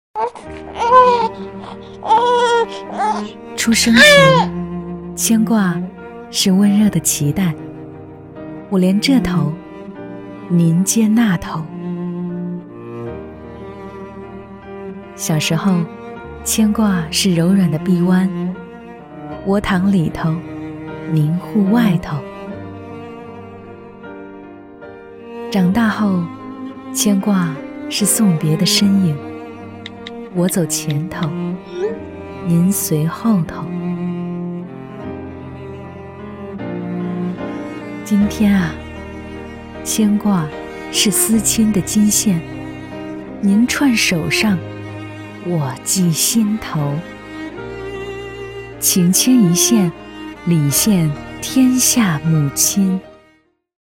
女15-【广告】母亲节深情
女15--广告-母亲节深情.mp3